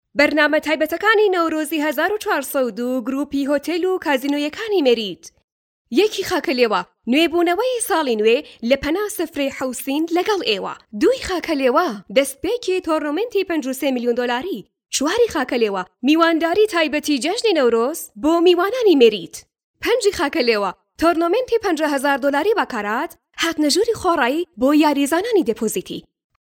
Female
Adult
Commercial